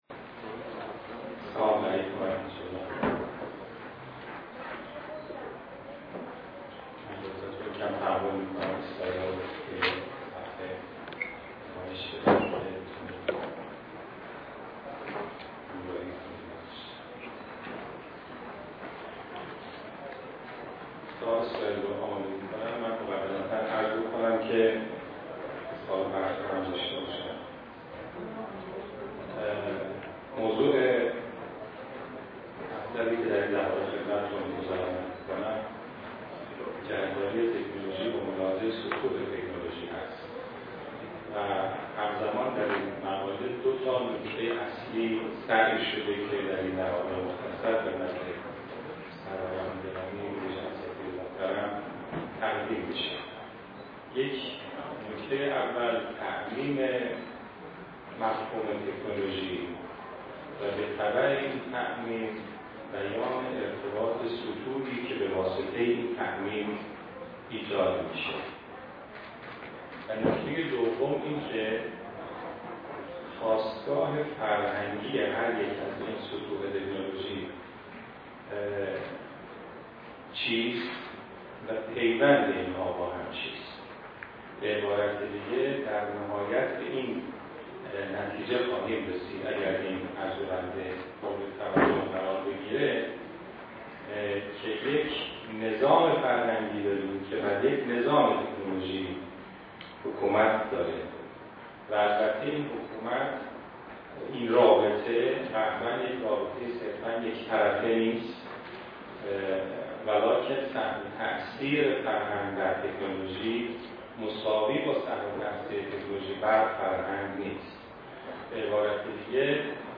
سخنرانی
در اولین همایش ملی فرهنگ و تکنولوژی